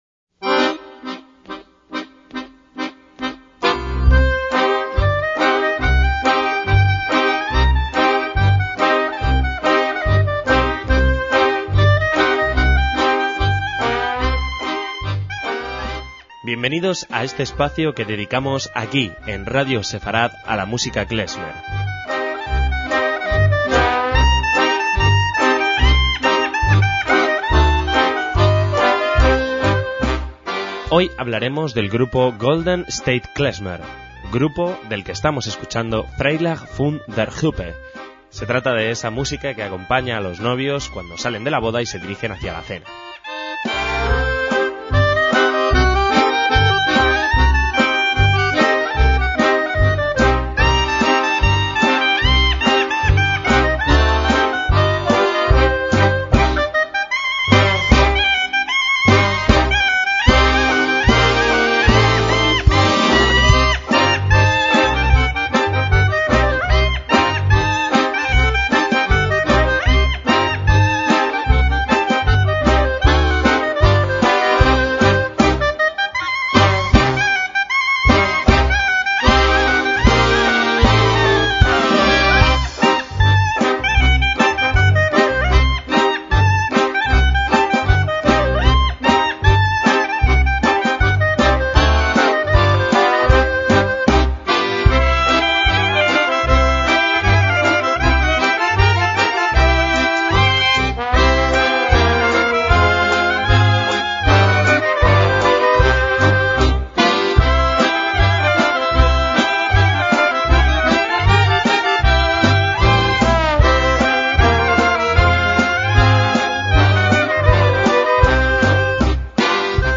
MÚSICA KLEZMER
acordeón, guitarra y contrabajo